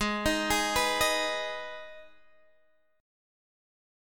G#m chord